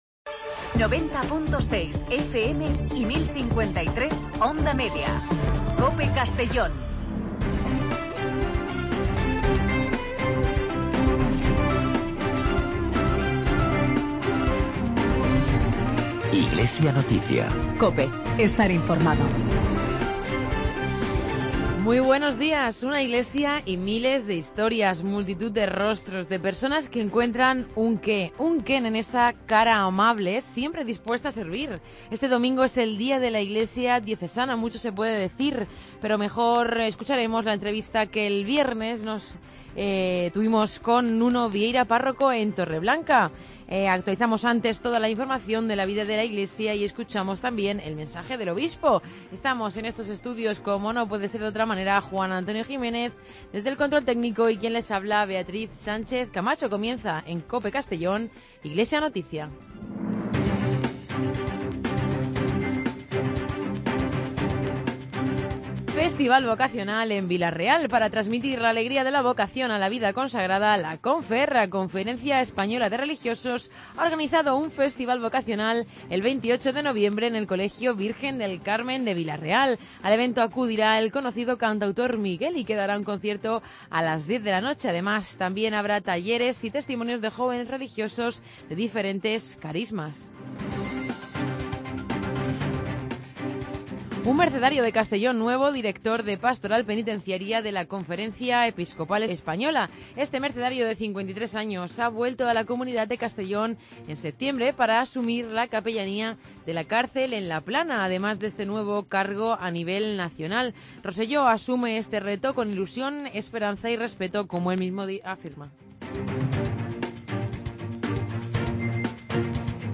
Espacio informativo de la actualidad diocesana de Segorbe-Castellón